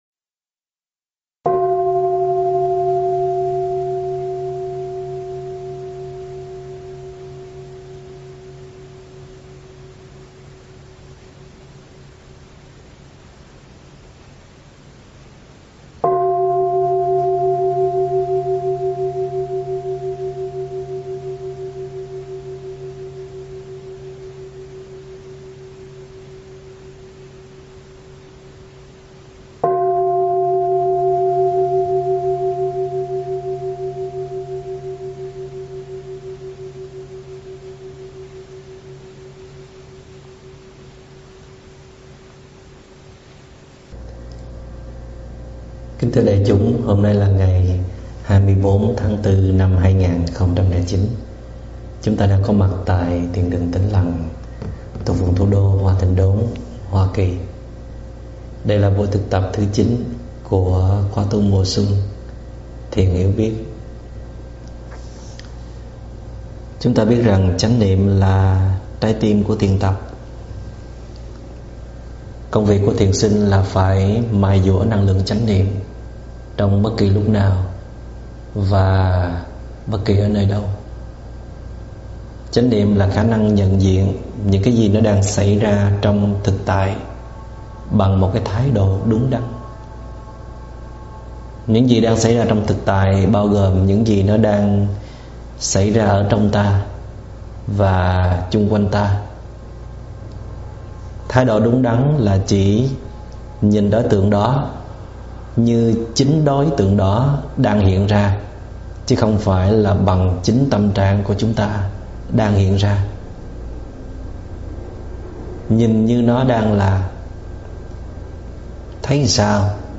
Thuyết pháp Nhìn đời bằng mắt trong - ĐĐ. Thích Minh Niệm
Mời quý phật tử nghe mp3 thuyết pháp Nhìn đời bằng mắt trong do ĐĐ. Thích Minh Niệm giảng tại chùa Hoa Nghiêm, Hoa Kỳ ngày 24 tháng 4 năm 2009